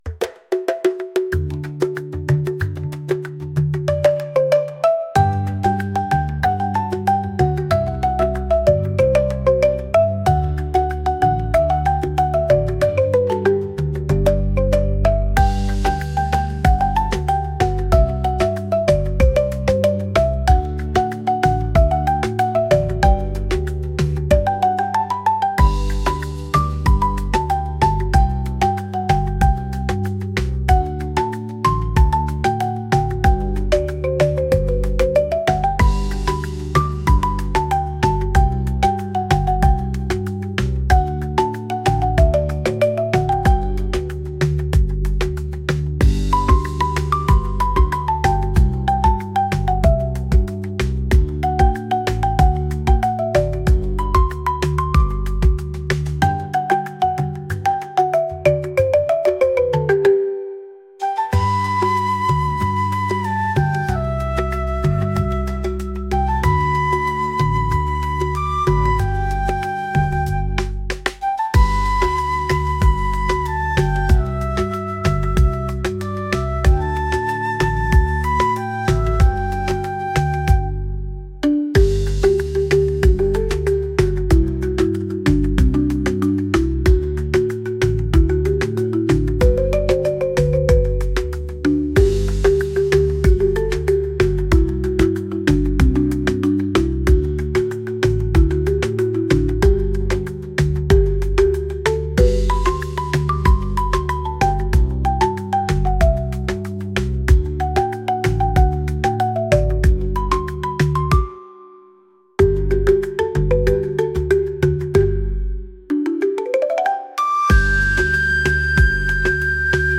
island | vibes | upbeat